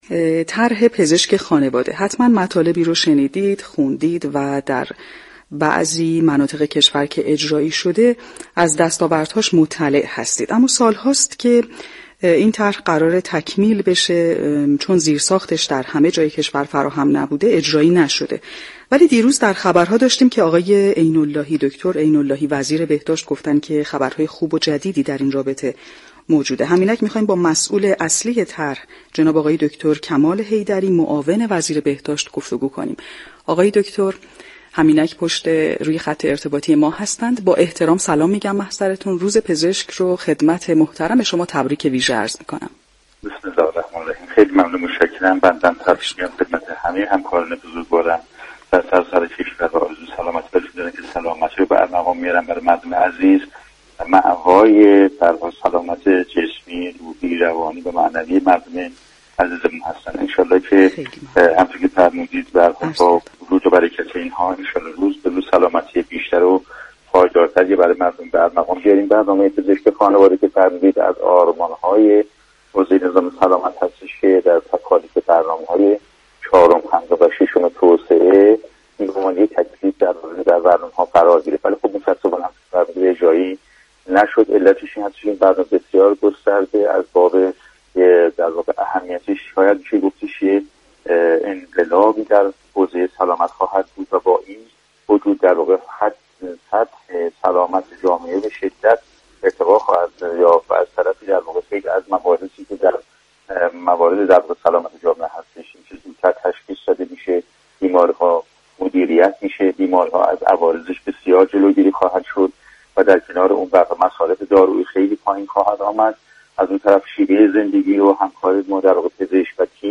به گزارش پایگاه اطلاع رسانی رادیو تهران، دكتر كمال حیدری معاون وزیر بهداشت ضمن تبریك روز پزشك و آرزوی سلامتی برای تمامی پزشكان درباره طرح فراگیر پزشك خانواده كه سالهاست به دلیل نبود زیرساخت‌های مناسب تكمیل نشده به برنامه تهران ما سلامت اول شهریورماه گفت: برنامه پزشك خانواده از آرمان‌های حوزه نظام سلامت است و در برنامه‌های 4 تا 6 توسعه قرار گرفت اما اجرایی نشد.